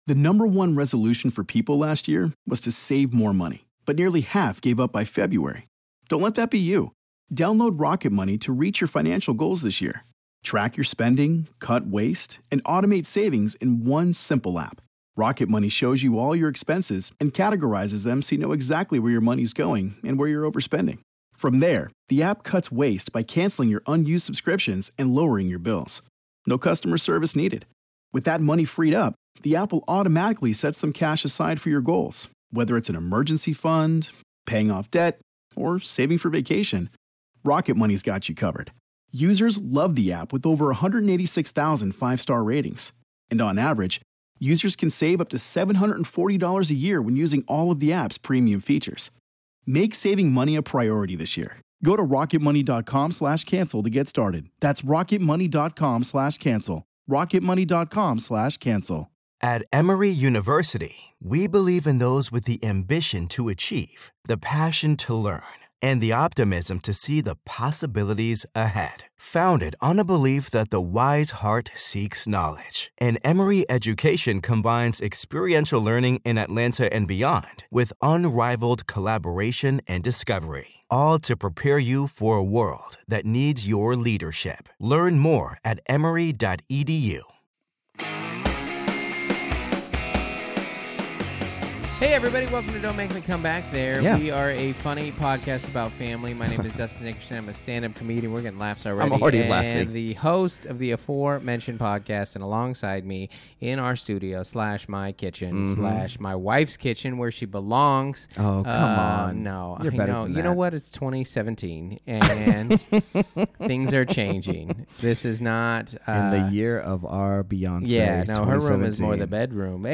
The topics are heavy but the laughs are plentiful.